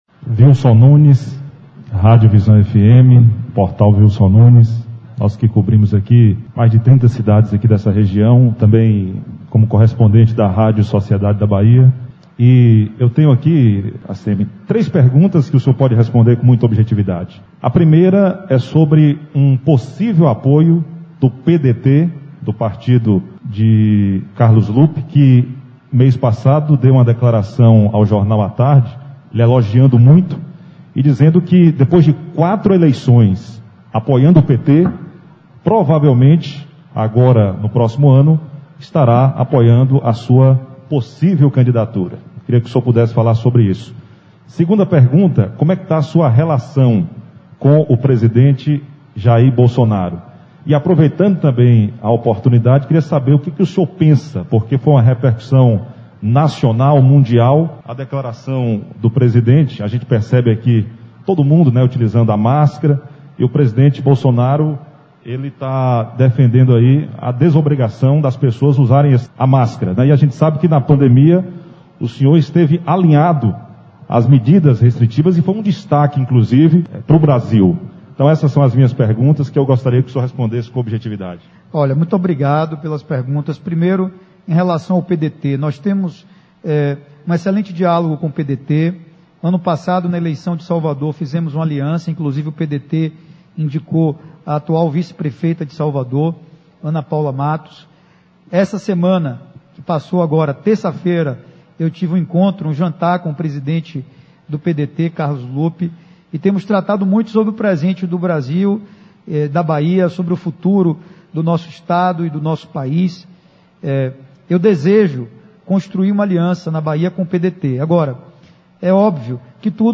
Já no final da tarde em Guanambi, concedeu coletiva de imprensa na Câmara de Vereadores.